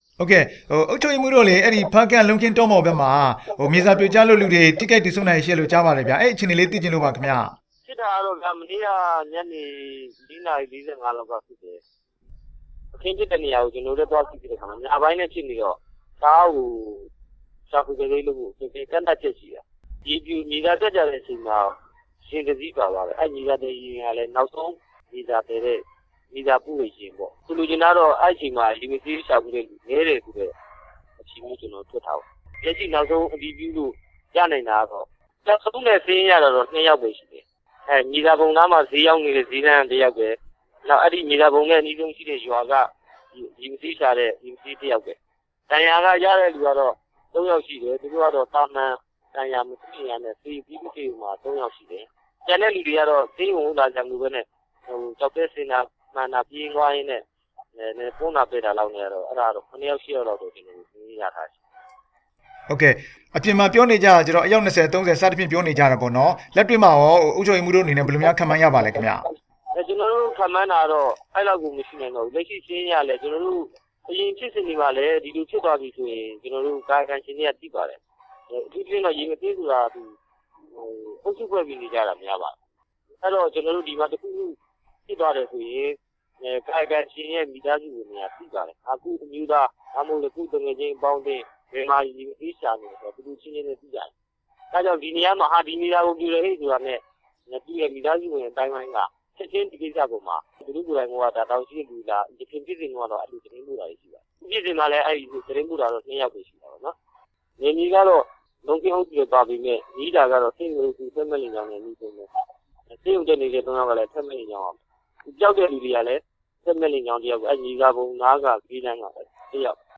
ဆက်သွယ်မေးမြန်းထားပါတယ်